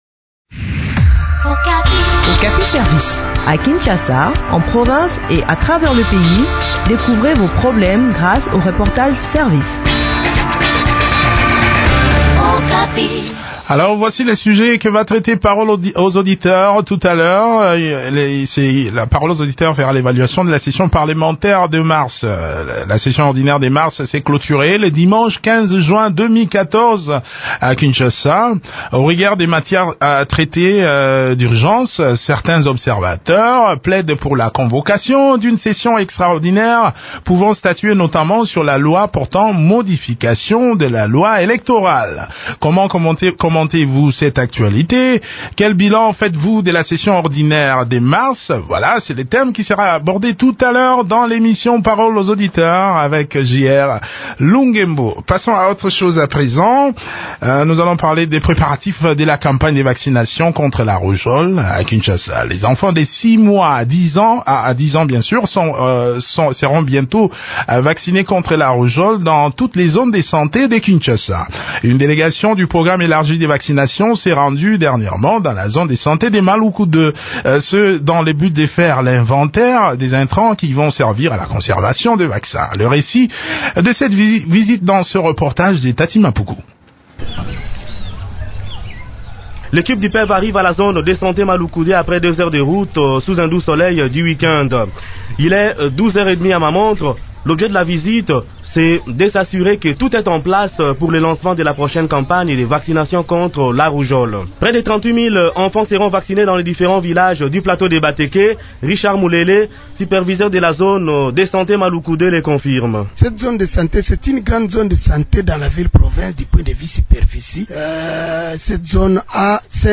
La campagne de vaccination contre la rougeole aura lieu à Kinshasa du 24 au 28 juin prochain. Le point du sujet dans cet entretien